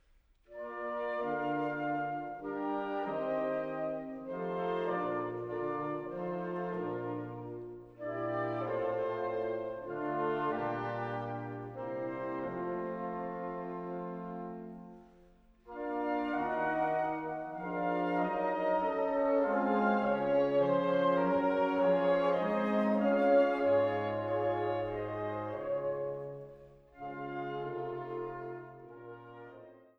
Der erste Satz beginnt präludienartig.
Lebhaft